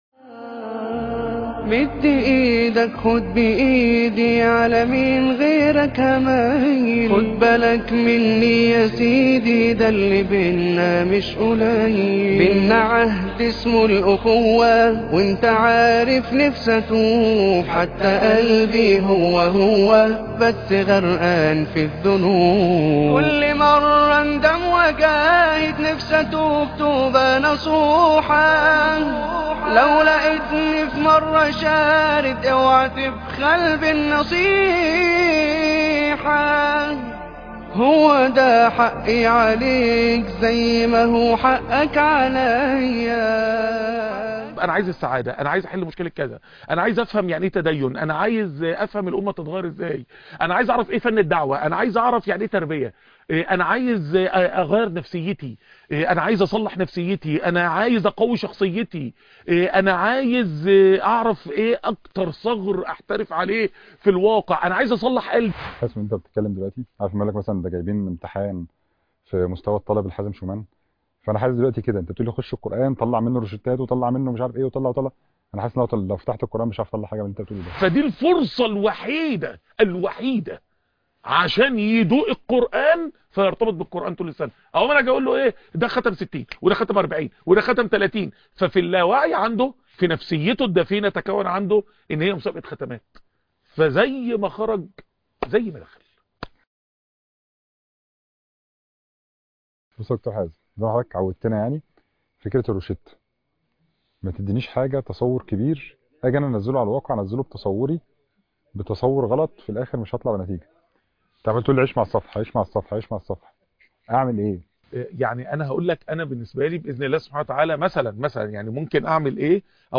الروشتة القرآنية _ حوار